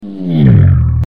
Car Passing By
Car_passing_by.mp3